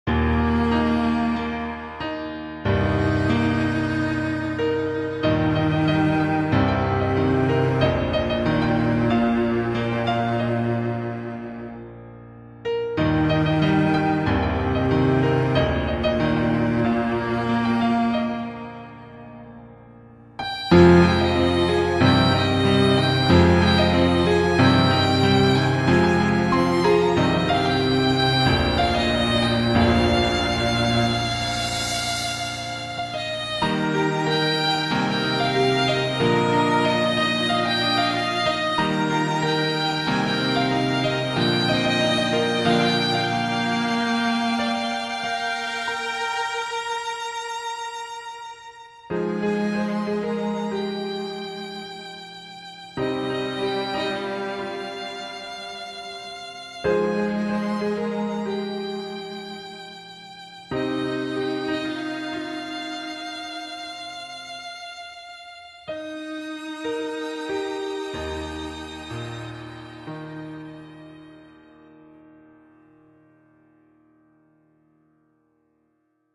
A mini orchestral version